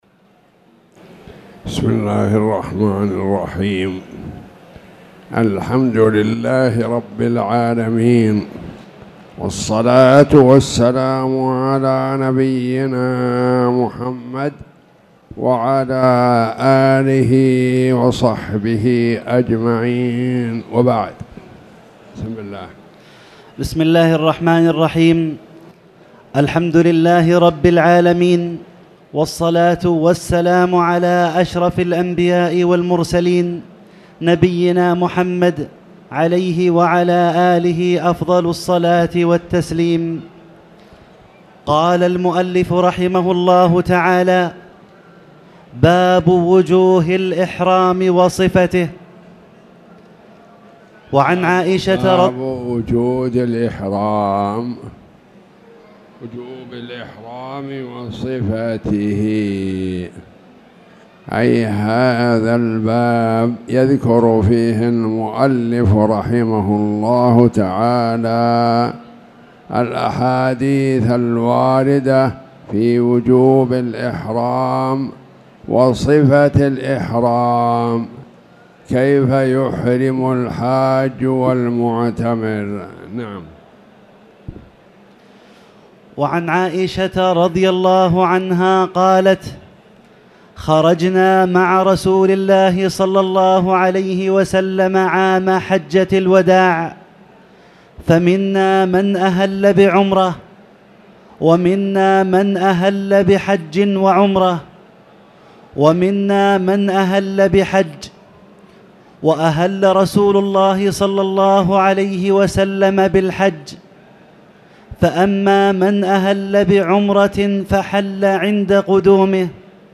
تاريخ النشر ١ جمادى الأولى ١٤٣٨ هـ المكان: المسجد الحرام الشيخ